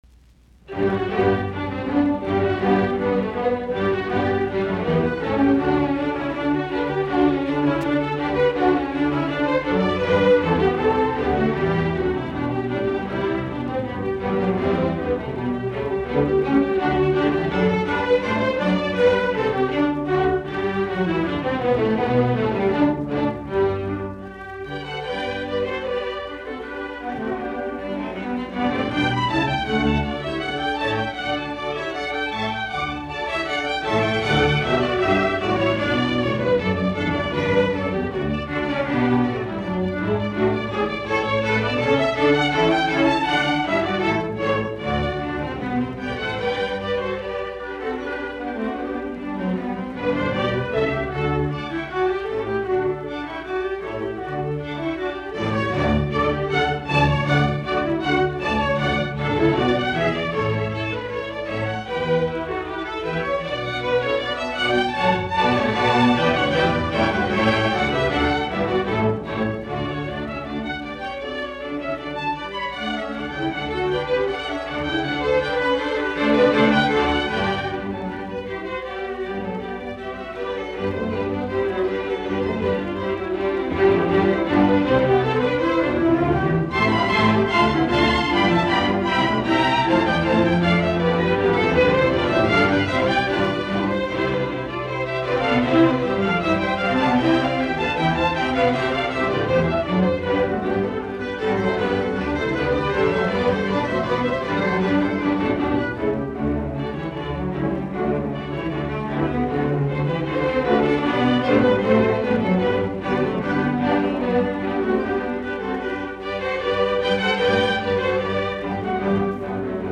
Allegro moderato